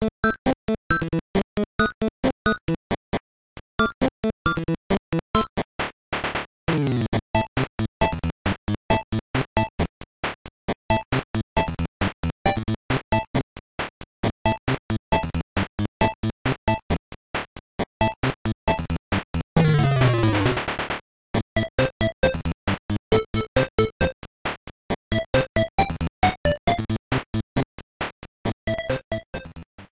Key A minor
BPM 130
is less upbeat and more slow-paced
with slightly lower-quality synthesized instrumentation